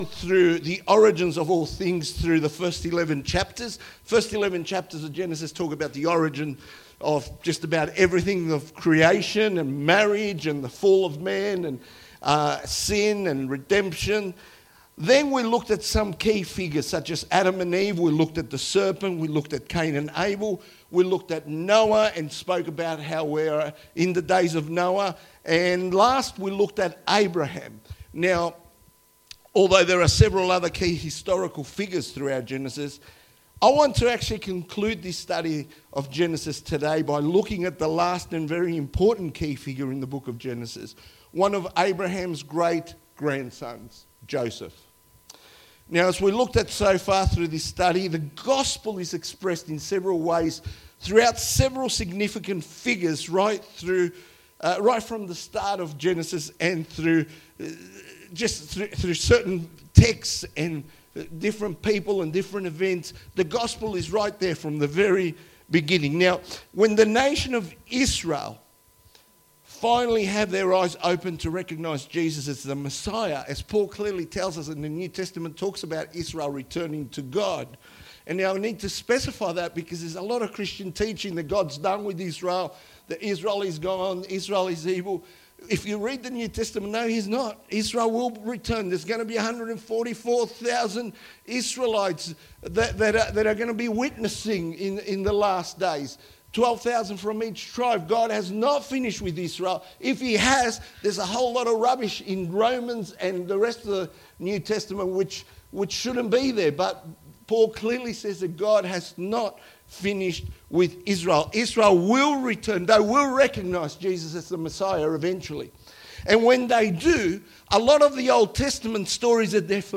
Wonthaggi Baptist Church
2025 • 27.00 MB Listen to Sermon Download this Sermon Download this Sermon To download this sermon